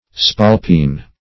Search Result for " spalpeen" : The Collaborative International Dictionary of English v.0.48: Spalpeen \Spal"peen\, n. [Ir. spailpin, fr. spailp a beau, pride, self-conceit.]